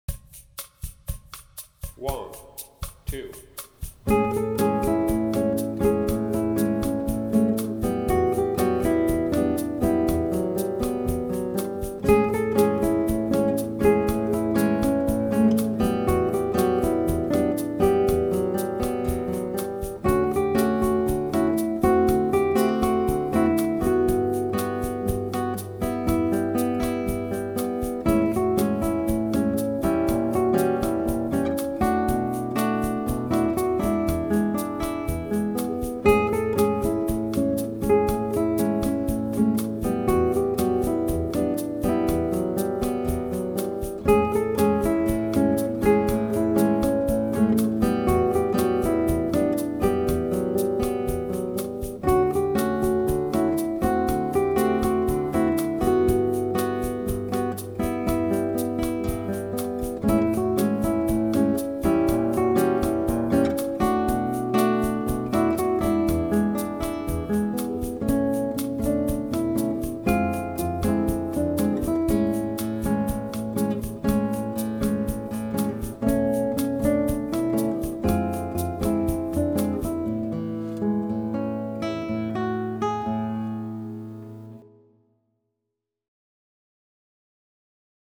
Duo-Version in Normalgeschwindigkeit